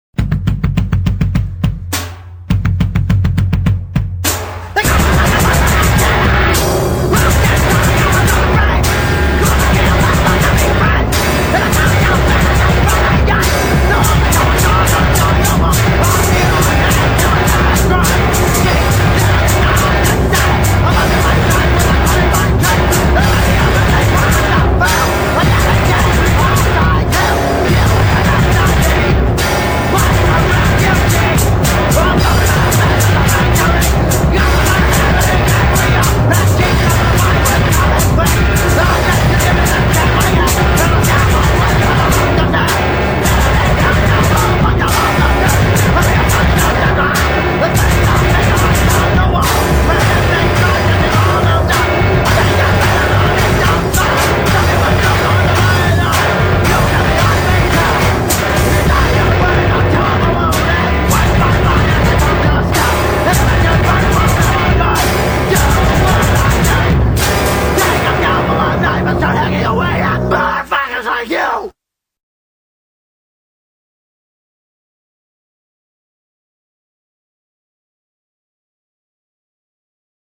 it's actually a neo-no wave band who'll stop to spazz around